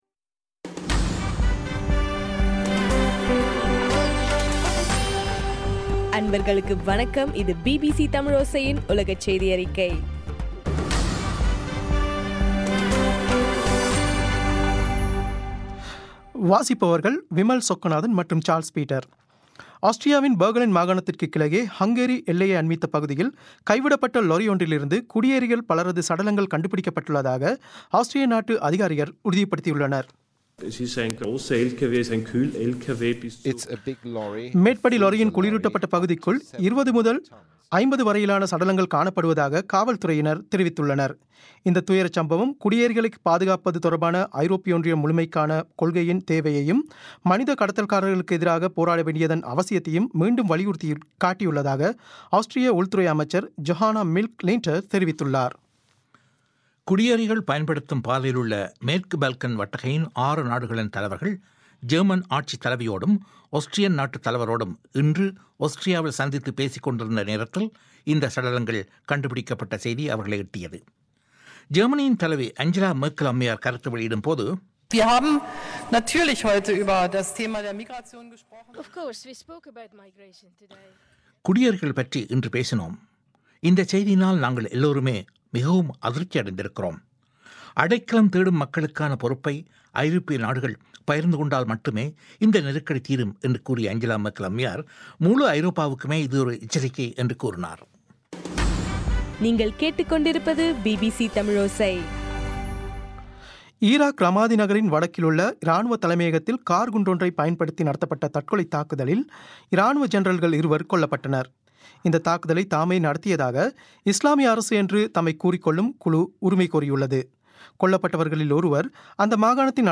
ஆகஸ்ட் 27 பிபிசியின் உலகச் செய்திகள்